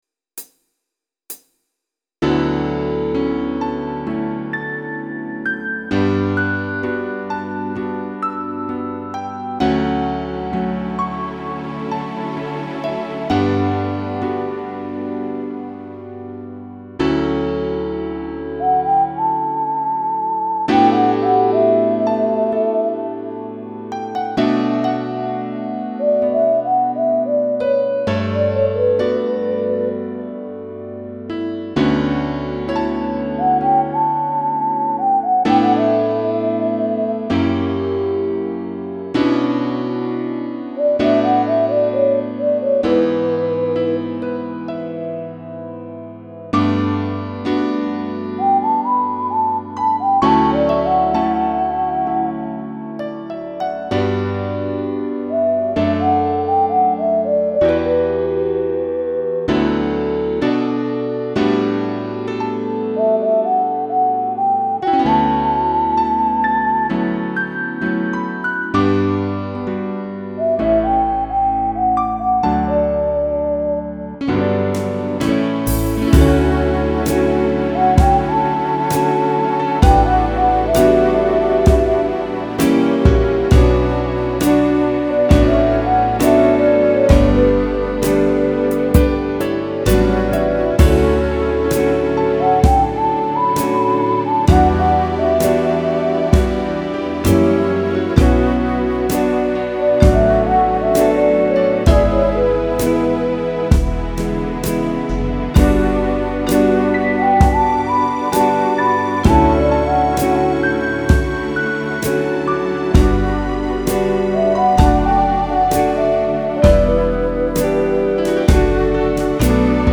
(Country and Jazz-Swing list)